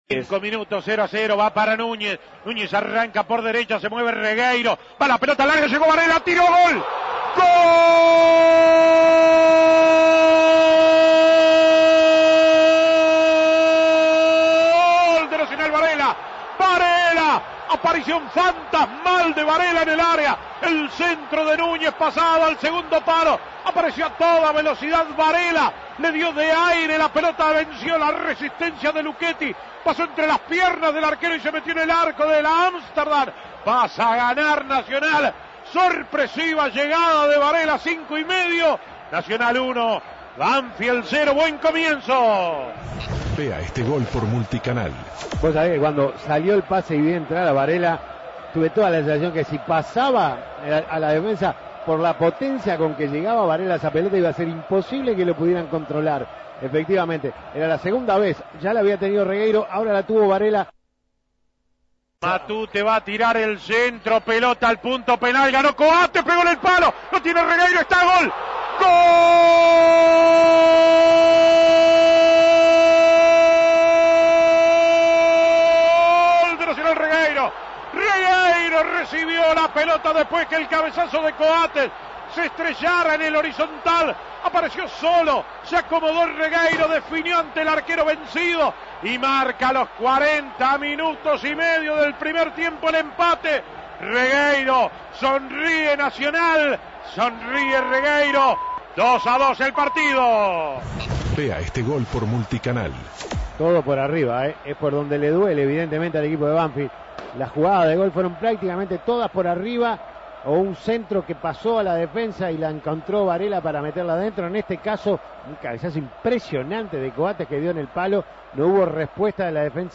Goles y comentarios ESCUCHE LOS GOLES DE NACIONAL A BANFIELD Imprimir A- A A+ Nacional empató 2 a 2 con Banfield en su segunda presentación de la Copa Libertadores.